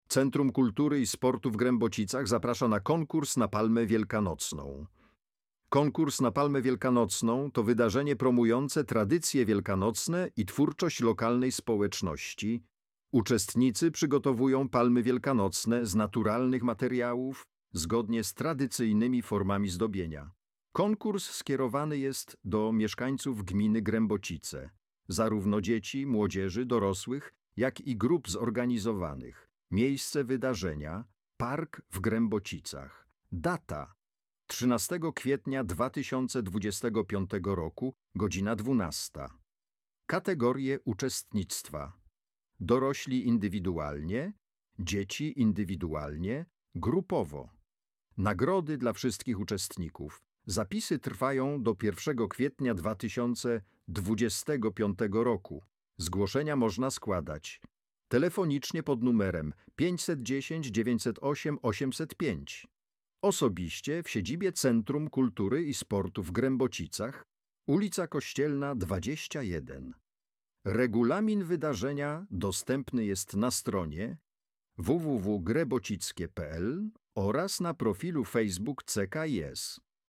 palma-wielkanocna-lektor.mp3